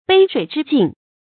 杯水之敬 注音： ㄅㄟ ㄕㄨㄟˇ ㄓㄧ ㄐㄧㄥˋ 讀音讀法： 意思解釋： 猶言杯水之謝。